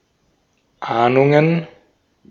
Ääntäminen
Ääntäminen Tuntematon aksentti: IPA: /ˈʔaːnʊŋən/ Haettu sana löytyi näillä lähdekielillä: saksa Käännöksiä ei löytynyt valitulle kohdekielelle. Ahnungen on sanan Ahnung monikko.